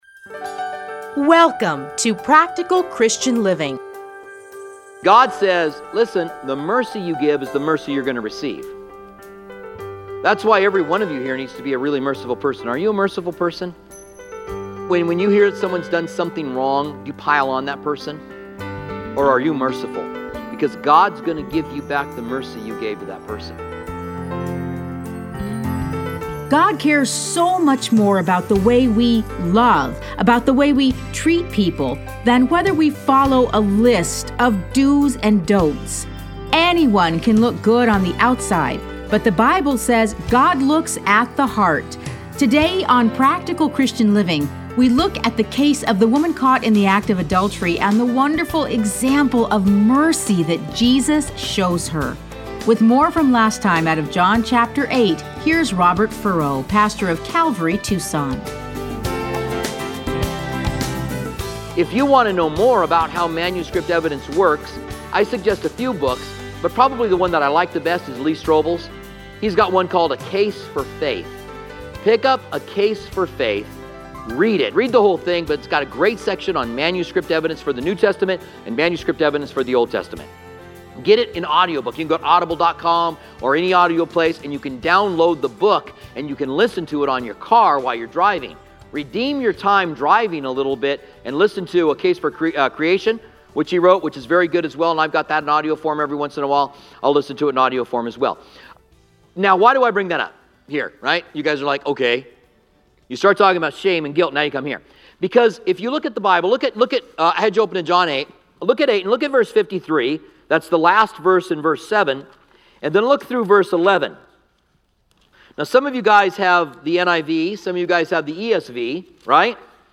Listen to a teaching from John 8:1-11.